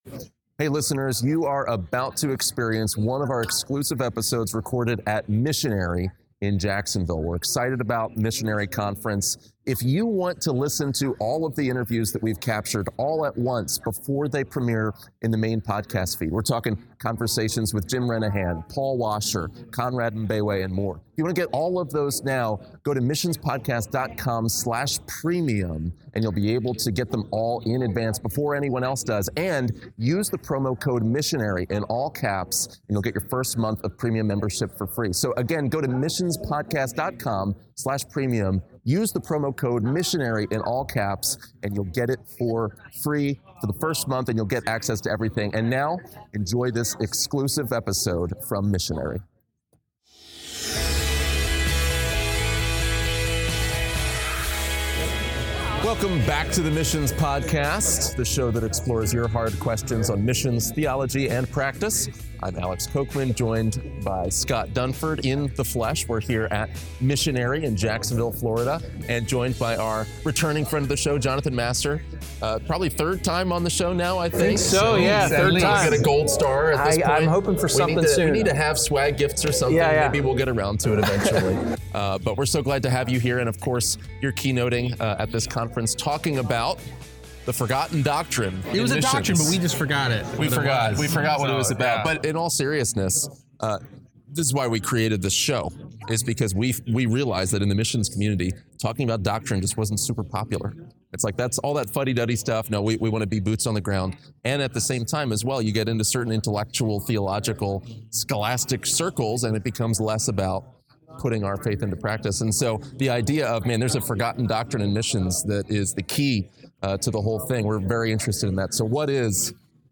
Is there a doctrine that’s been forgotten by the contemporary church? In this exclusive interview from Missionary